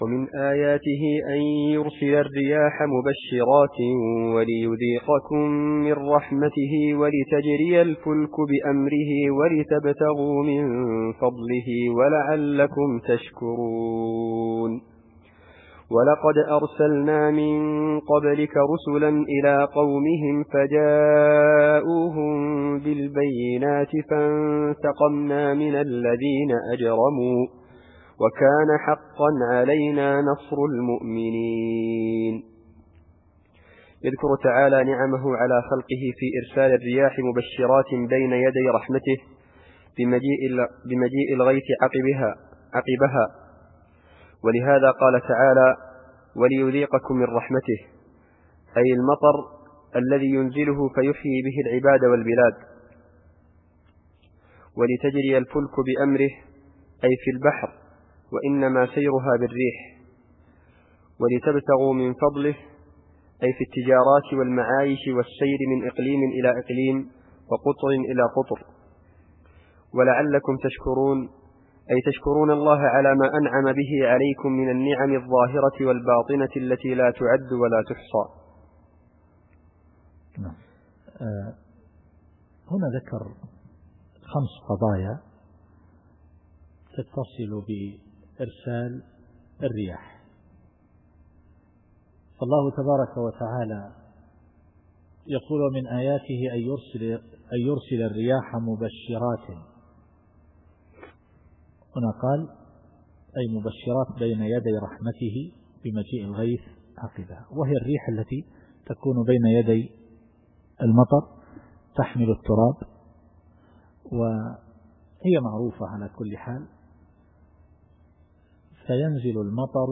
التفسير الصوتي [الروم / 46]